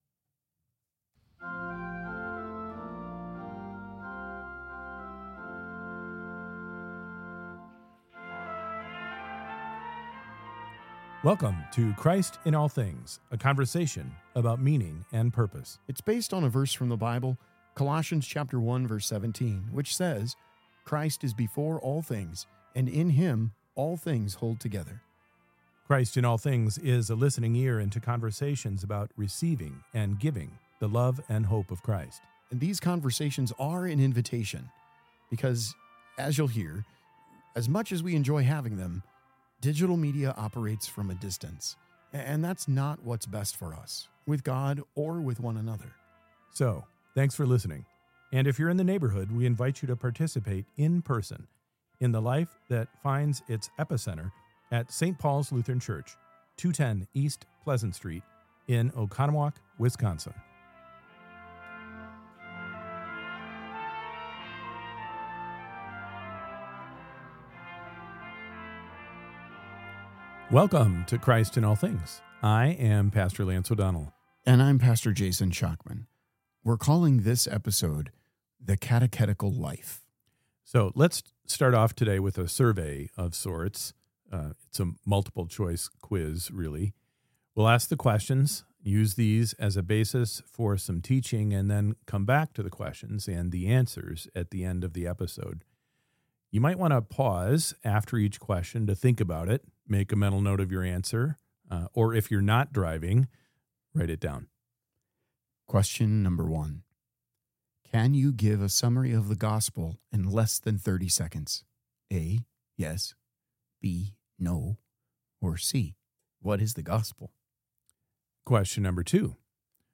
The Catechetical Life is an “audio brochure” of sorts.